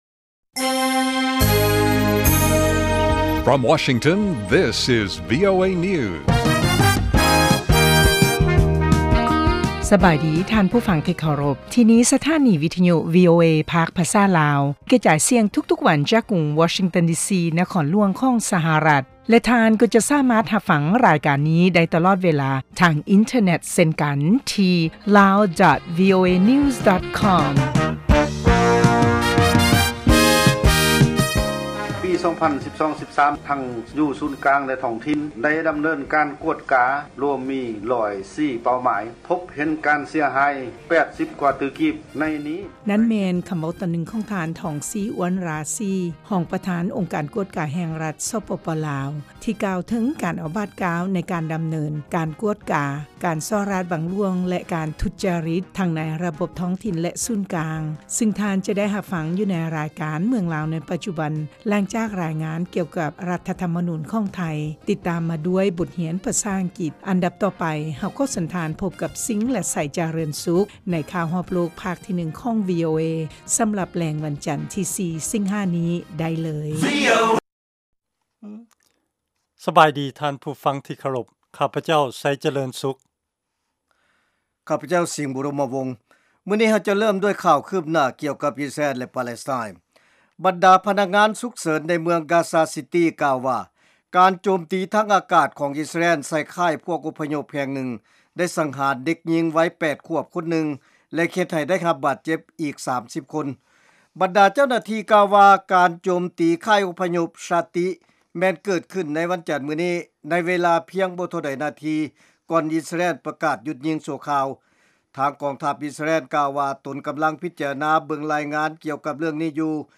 ພວກເຮົາສະເໜີຂ່າວ ຂໍ້ມູນ ແລະລາຍງານທີ່ໜ້າສົນໃຈ ກ່ຽວກັບເຫດການທີ່ເກີດຂຶ້ນໃນປະເທດລາວ ສະຫະລັດອາເມຣິກາ ເອເຊຍແລະຂົງເຂດອື່ນໆຂອງໂລກ ຕະຫລອດທັງບົດຮຽນພາສາອັງກິດ ແລະລາຍການເພງຕາມຄຳຂໍຂອງທ່ານຜູ້ຟັງ. ຕາລາງເວລາອອກອາກາດ ທຸກໆມື້ ເວລາທ້ອງຖິ່ນໃນລາວ 07:30 ໂມງແລງ ເວລາສາກົນ 1230 ຄວາມຍາວ 00:30:00 ຟັງ: MP3